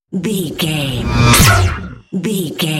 Sci fi pass by shot
Sound Effects
futuristic
pass by
vehicle